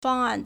方案 fāngàn
fang1an4.mp3